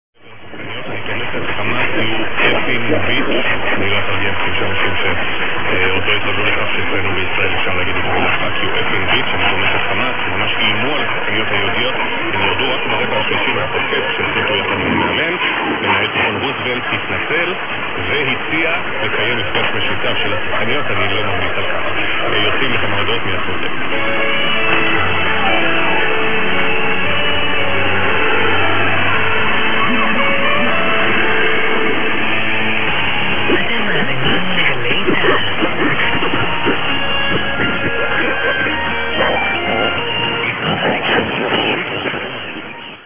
the ID Galai Zahal at sec 34.